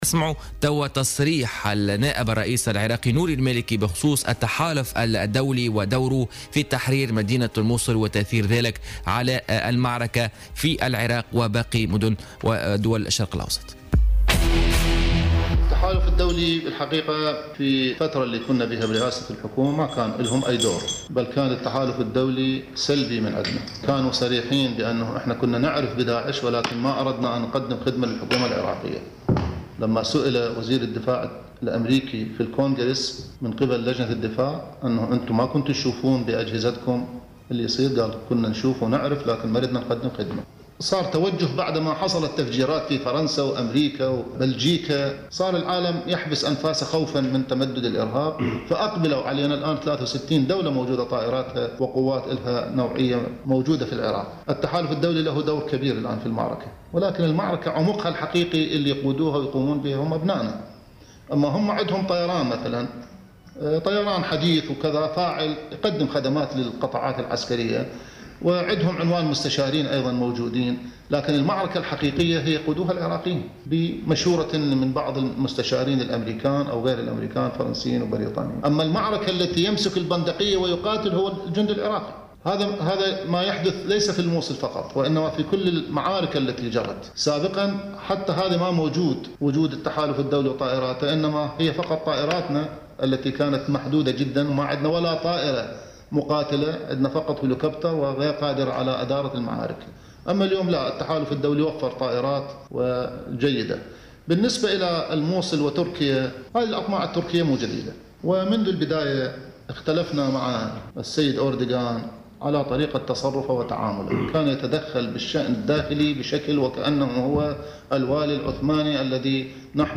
وتابع في تصريح لموفد "الجوهرة أف أم"